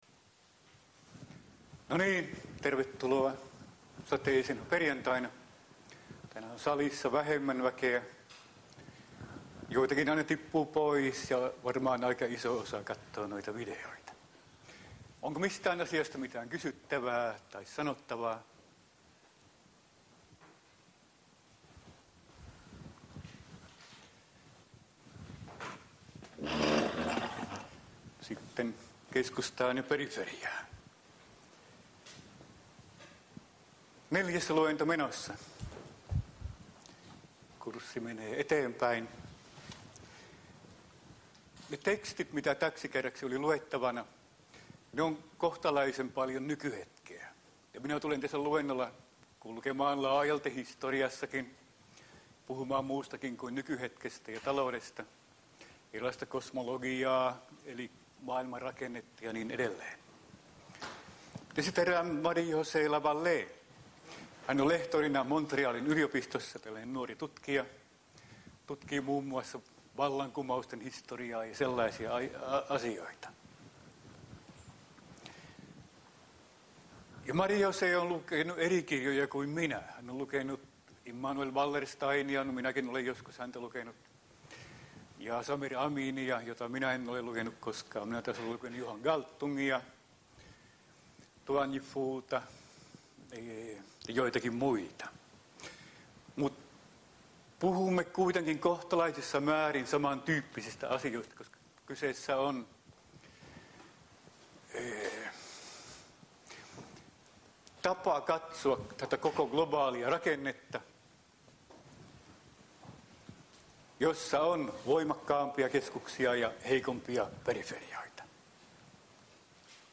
POLS3017 Luento 4 — Moniviestin